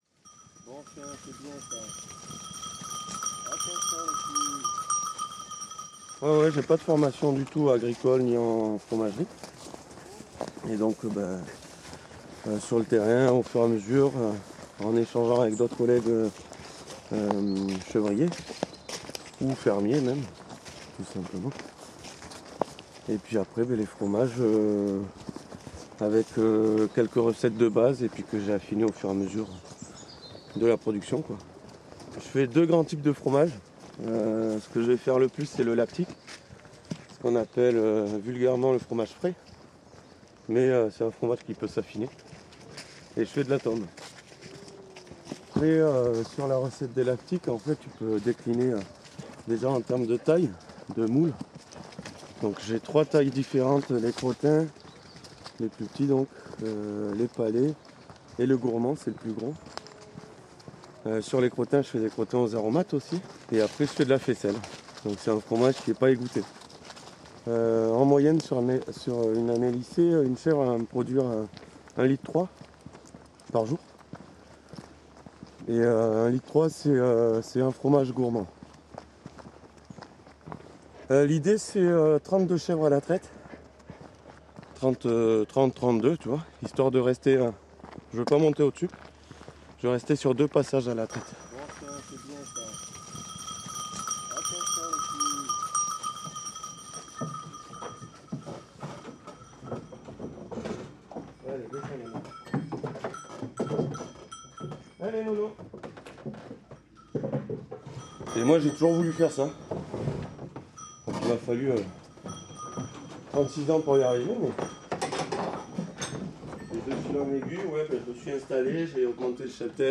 portrait sonore